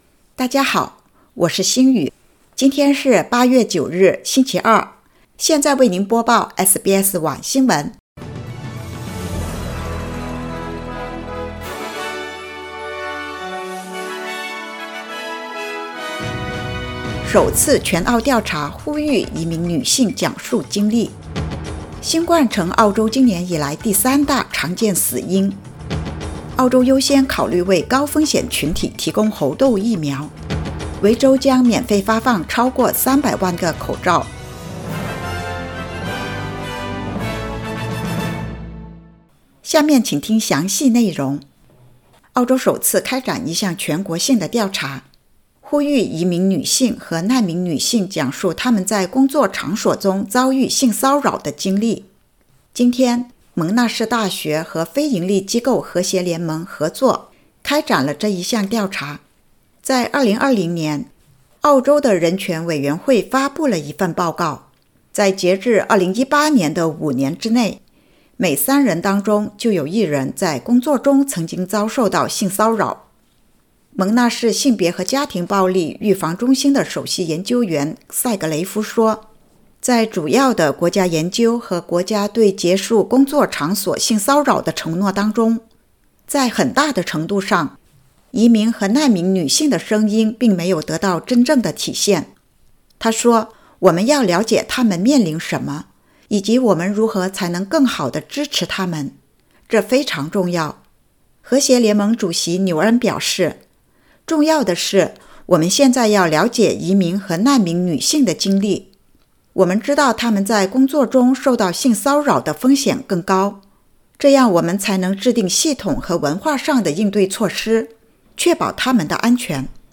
SBS晚新闻（2022年8月9日）